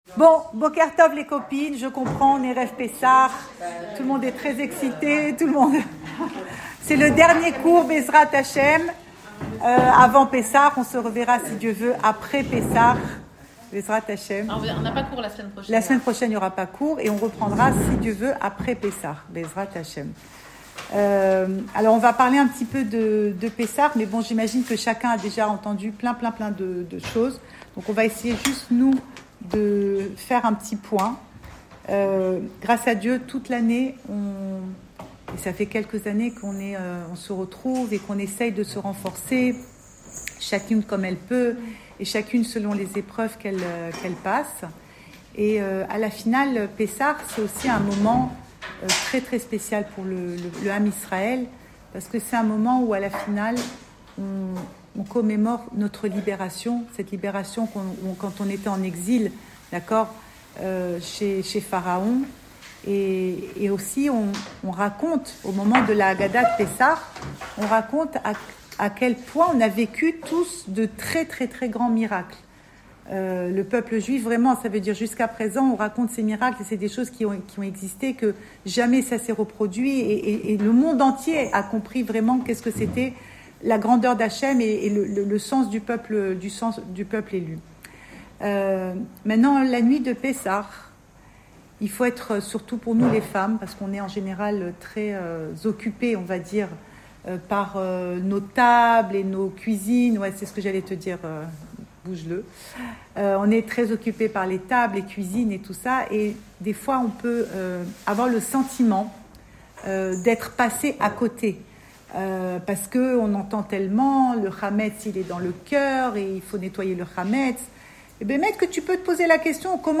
Cours audio Fêtes Le coin des femmes Le fil de l'info - 2 avril 2025 2 avril 2025 Pessah : mon Seder à moi. Enregistré à Tel Aviv